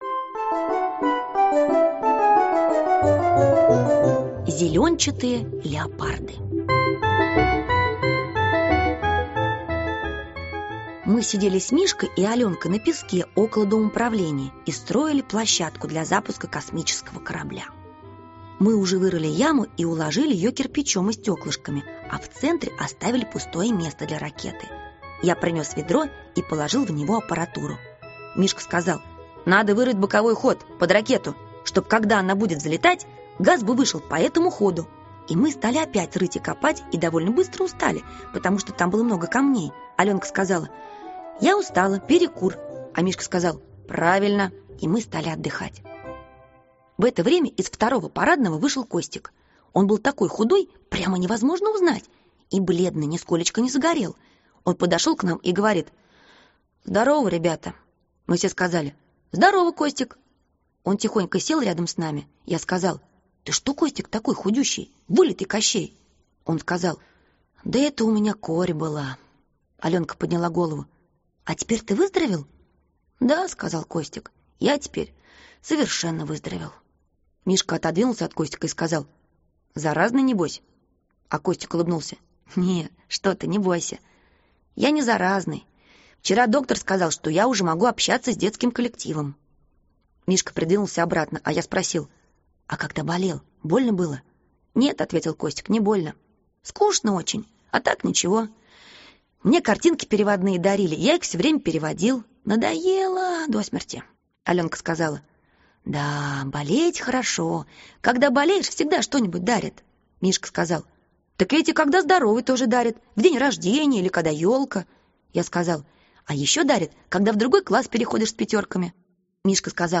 Аудиокнига Похититель собак | Библиотека аудиокниг